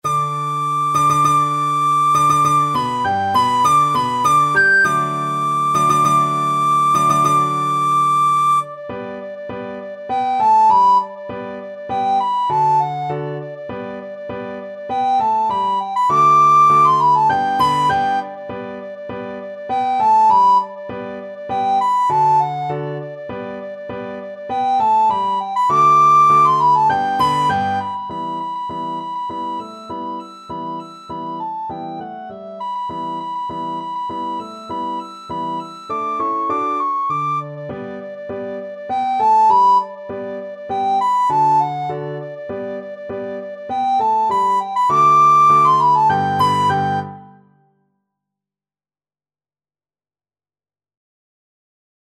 Free Sheet music for Soprano (Descant) Recorder
G major (Sounding Pitch) (View more G major Music for Recorder )
4/4 (View more 4/4 Music)
=200 Presto (View more music marked Presto)
Classical (View more Classical Recorder Music)
rossini_william_tell_REC.mp3